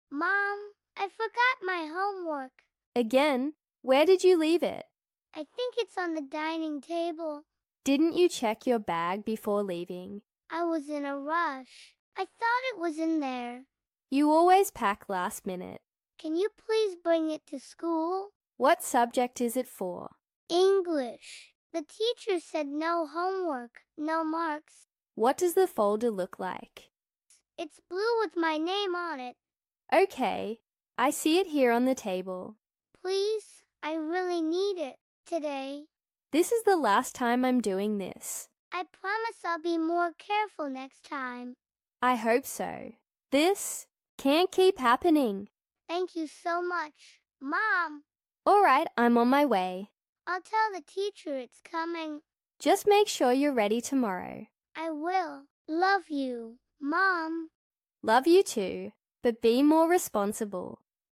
Mp3 Sound Effect Mom, I firgorget my homework. speaking practice through dialogue.